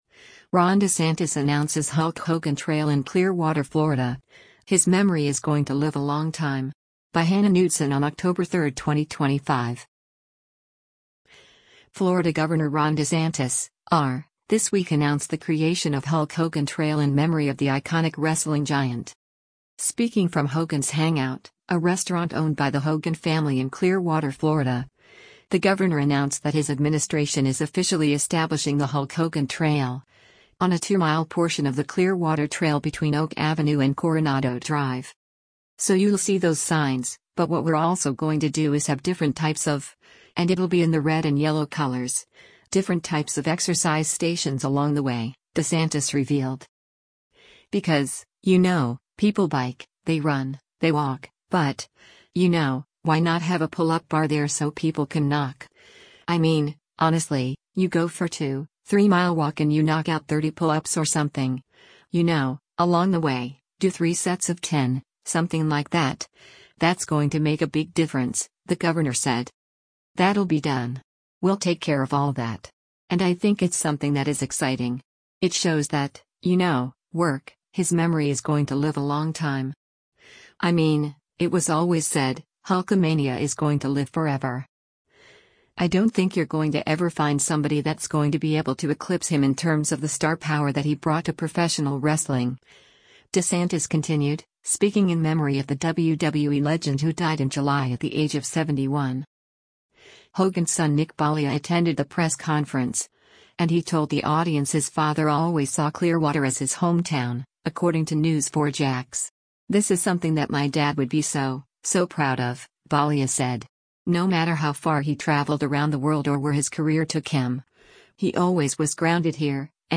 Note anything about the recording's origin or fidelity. Speaking from Hogan’s Hangout, a restaurant owned by the Hogan family in Clearwater, Florida, the governor announced that his administration is officially establishing the “Hulk Hogan Trail,” on a two-mile portion of the Clearwater Trail between Oak Avenue and Coronado drive.